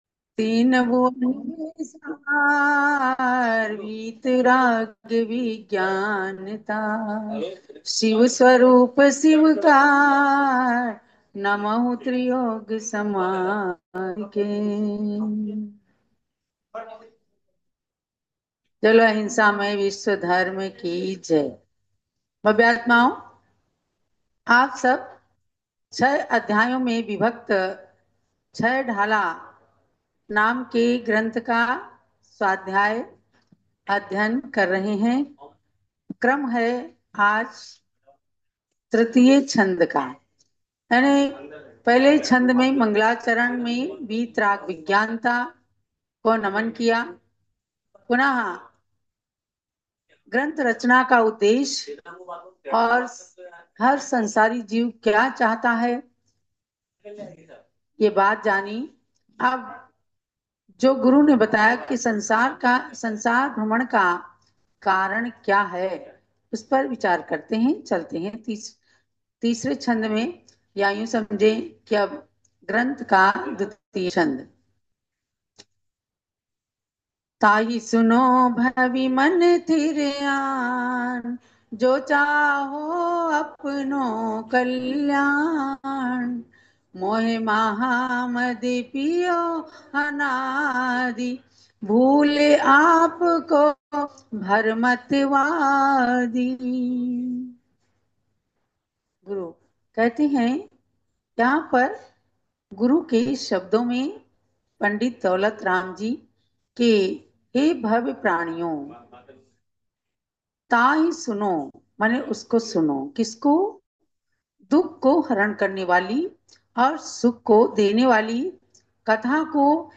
छहढाला क्लास